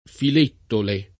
vai all'elenco alfabetico delle voci ingrandisci il carattere 100% rimpicciolisci il carattere stampa invia tramite posta elettronica codividi su Facebook Filettole [ fil $ ttole o fil % ttole ] top.
— pn. loc. con -e- aperta per F. di Prato, con -e- chiusa per F. presso Pisa